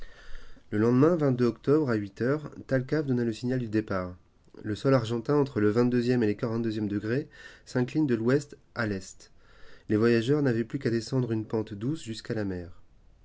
male_10032.wav